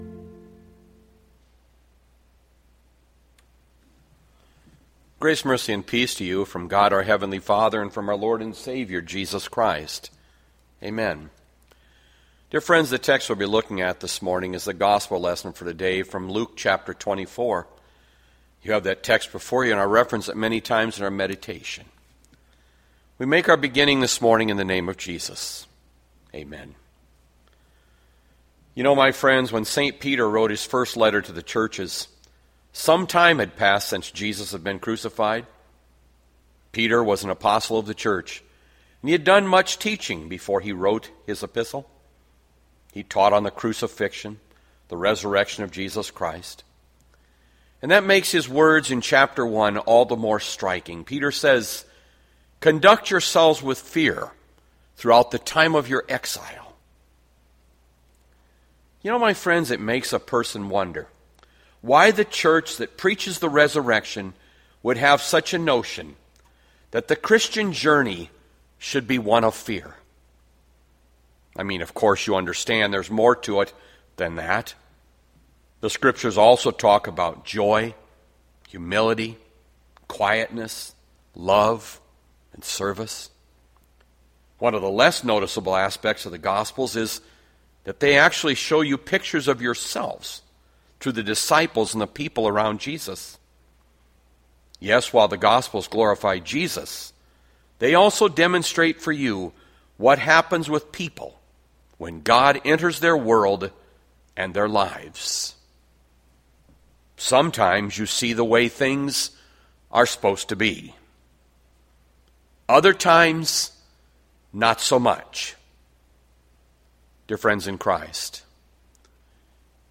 Bethlehem Lutheran Church, Mason City, Iowa - Sermon Archive Apr 26, 2020